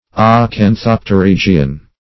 Acanthopterygian \Ac`an*thop`ter*yg"i*an\, a. (Zool.)